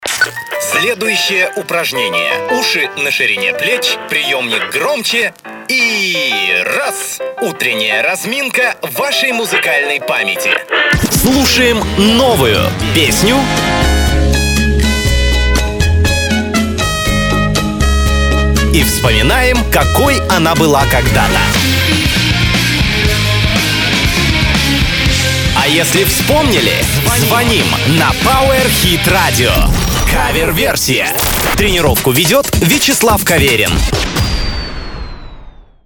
Столкнулся с проблемой при записи демки (Deathcore).
Проблема заключается вот в чем: В треке две гитары (на каждую даблтрэк) , бас-гитара (1 дорожка), ударные (Superior Drummer) и еще отельной дорожкой соляки идут.
Гитары пишутся через преамп в линию...